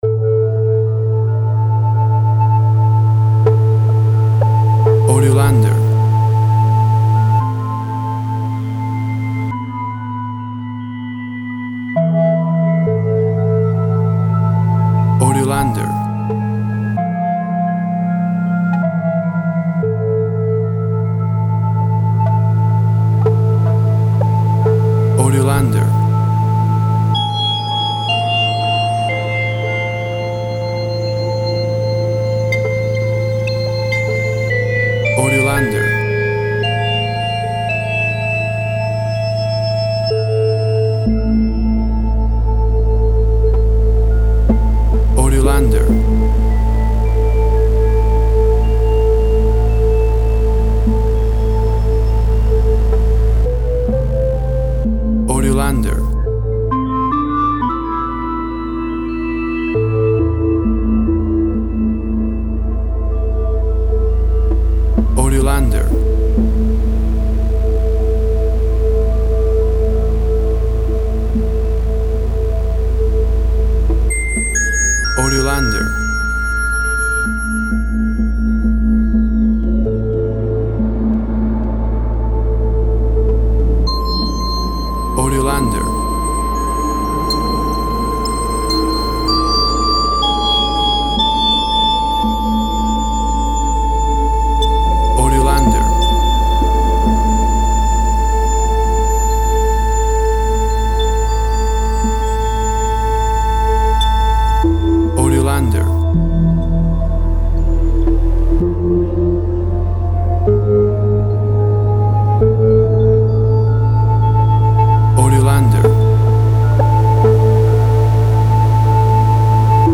Fear tension and suspense music.